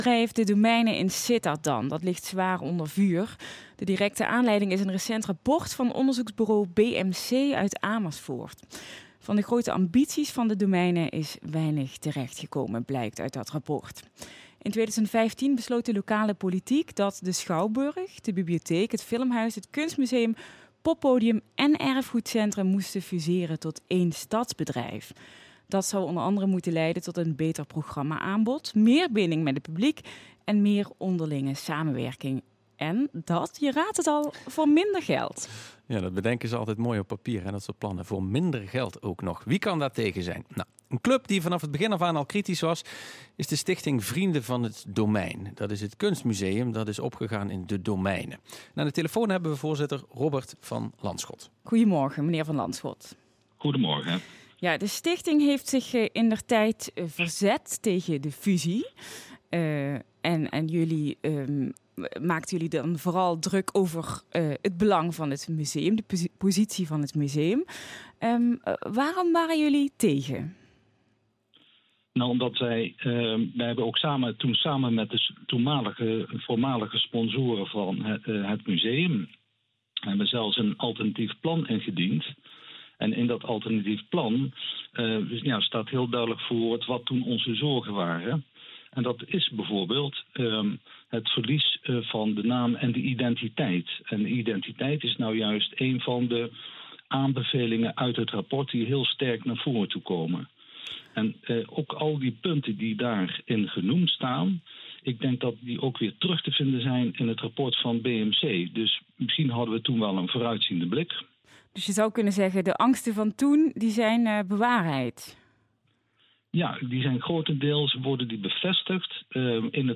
Interview L1